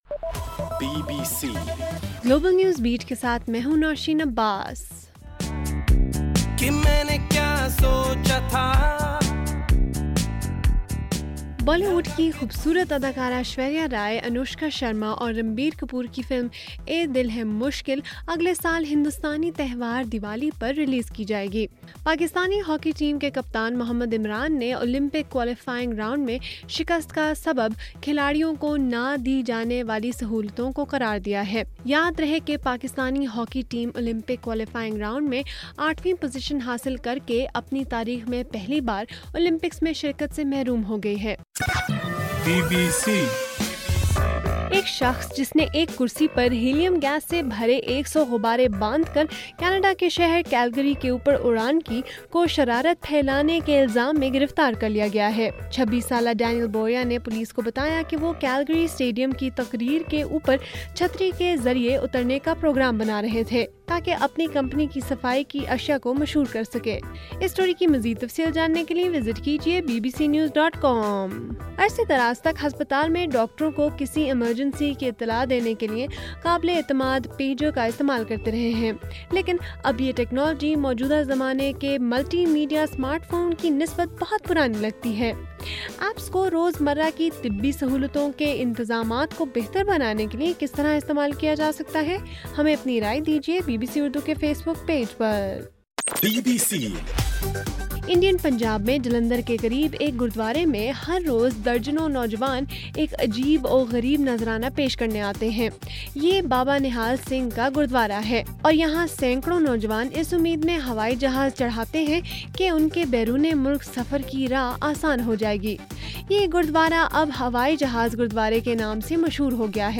جولائی 7: رات 10 بجے کا گلوبل نیوز بیٹ بُلیٹن